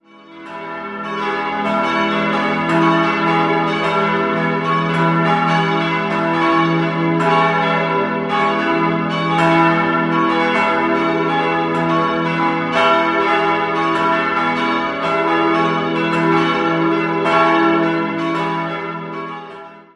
4-stimmiges Geläut: e'-gis'-h'-cis'' Die vier Glocken des Hauptgeläuts wurden 1970 von Friedrich Wilhelm Schilling in Heidelberg gegossen. Es gibt noch eine kleine, fünfte Glocke aus der alten Kirche, die als Sterbeglocke verwendet wird.